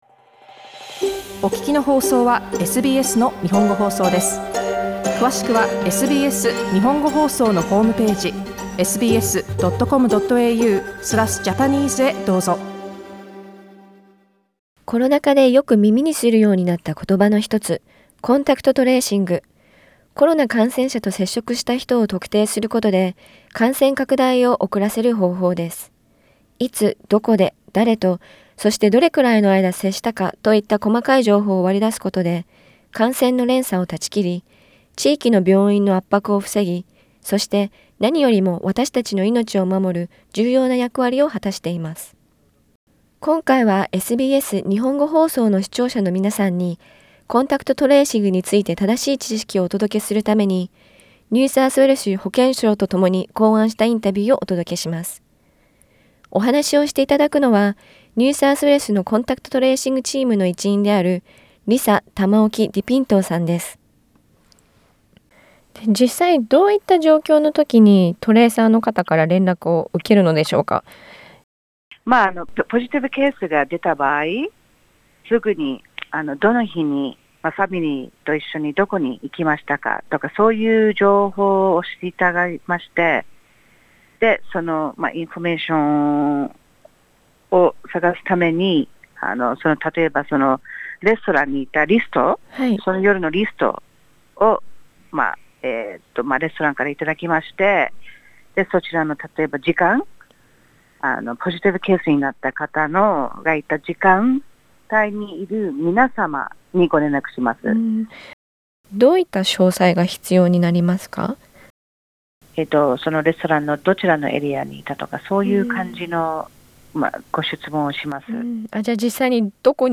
今回はコンタクト・トレーシングについて正しい知識をお届けするために、NSW保健相とともに考案したインタビューの内容をこちらにまとめます。